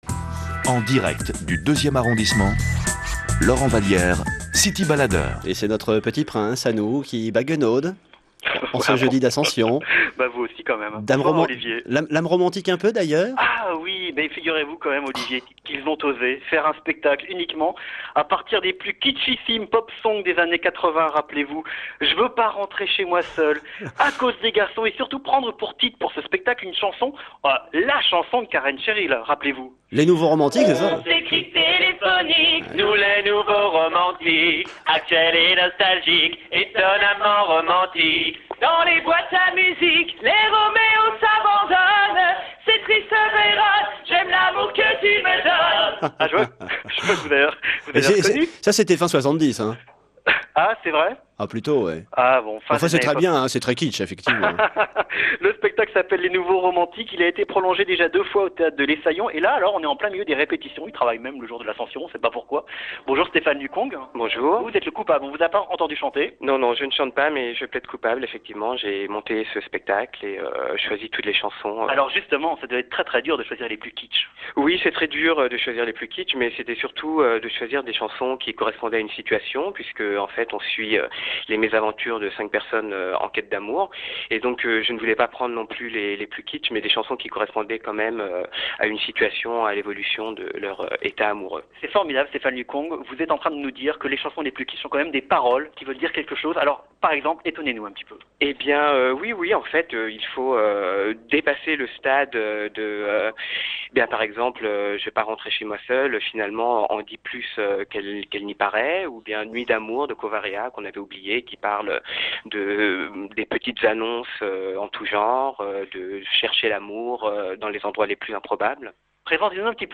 Reportage
cityradio.mp3